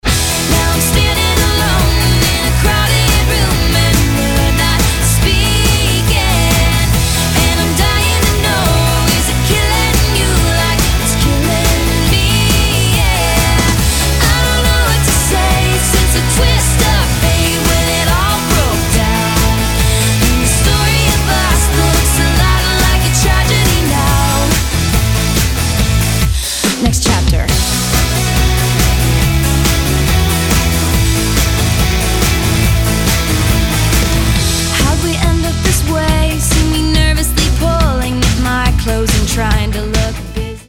pop-country speváčka